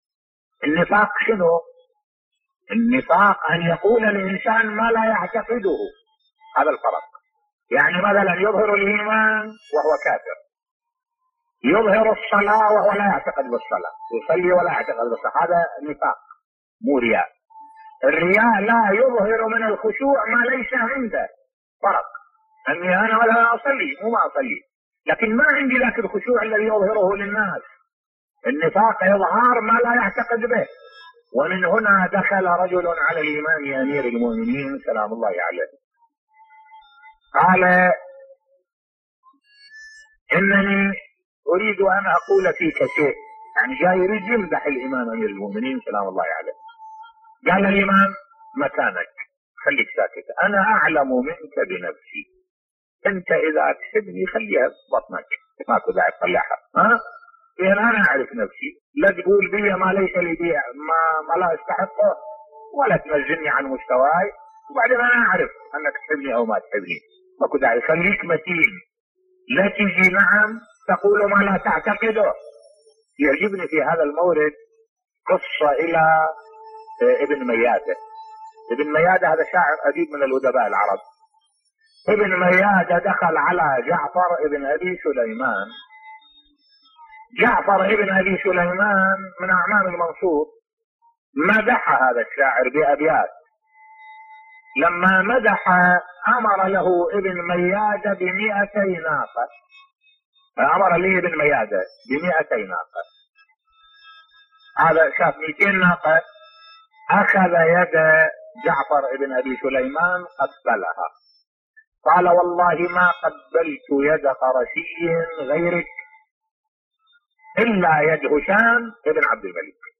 ملف صوتی ما الفرق بين النفاق و الرياء بصوت الشيخ الدكتور أحمد الوائلي